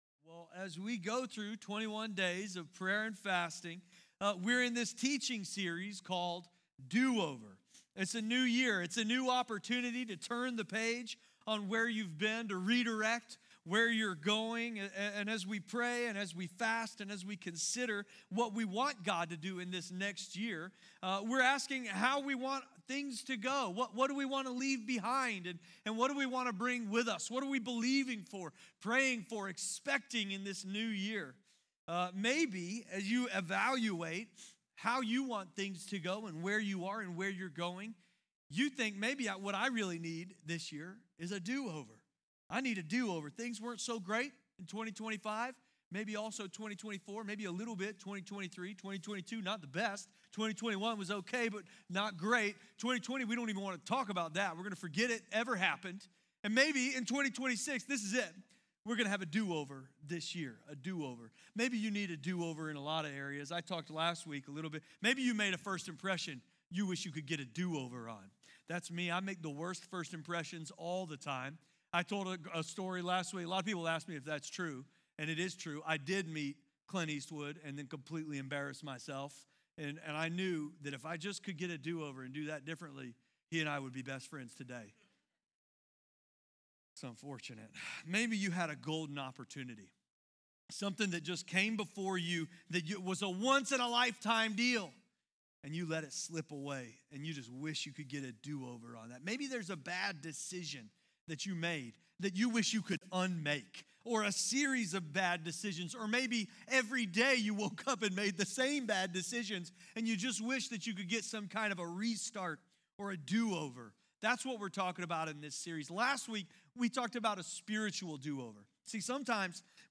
Do Over is a sermon series about God’s grace that meets us in our mistakes and offers a fresh start. No matter what’s behind you, God isn’t finished with your story.